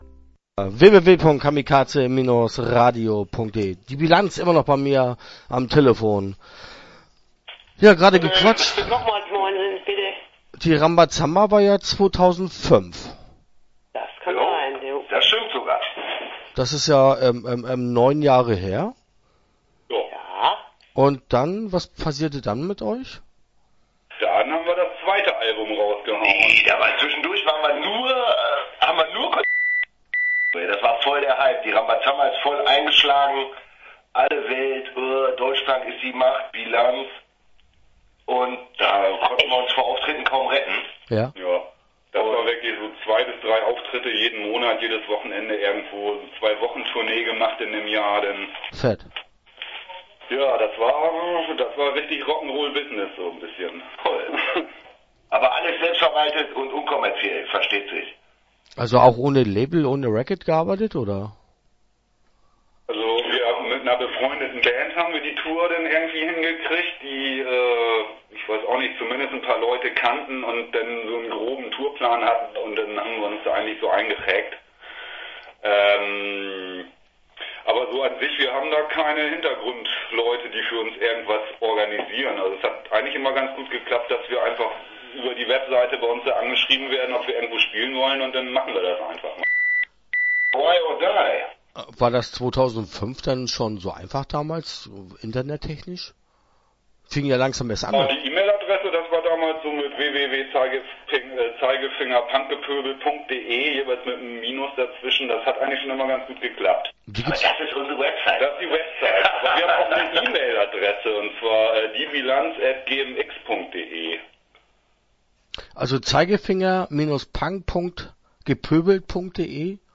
Die Bilanz - Interview Teil 1 (12:47)